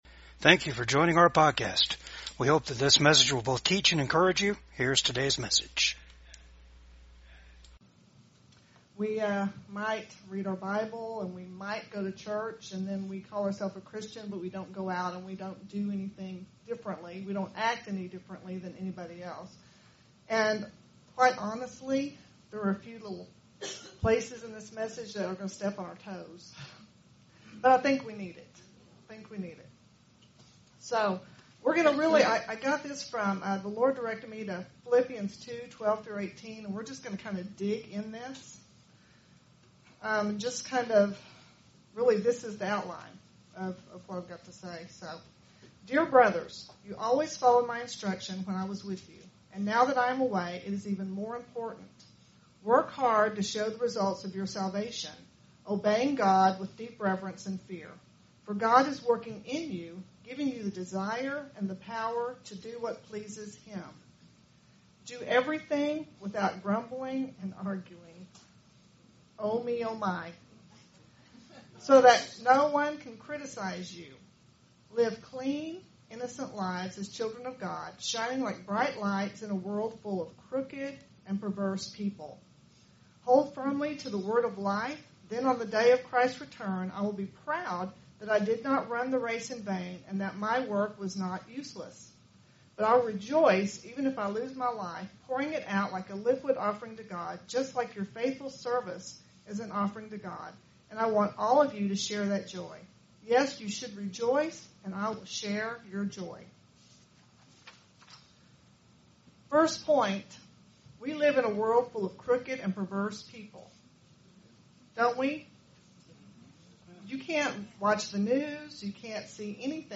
Philippians 2:12-18 Service Type: VCAG WEDNESDAY SERVICE When we let our light shine and shine brightly we are living our life as an offering to God.